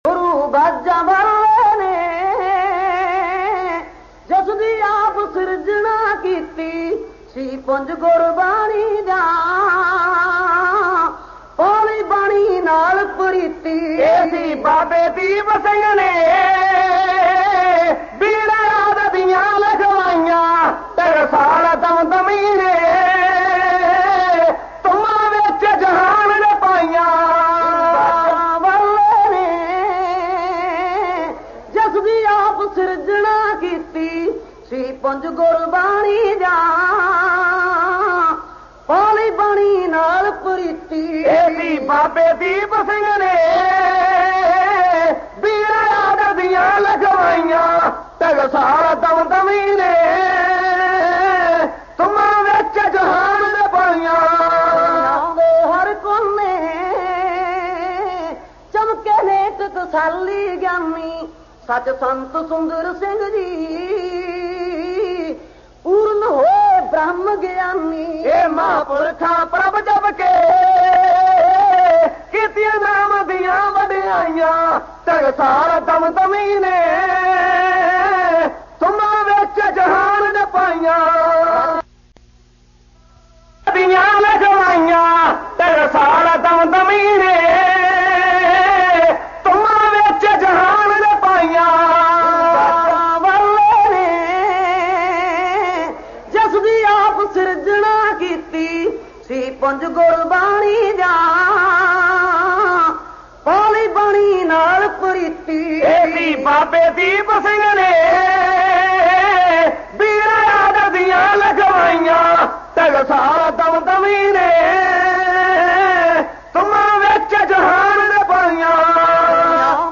Genre: Kavishri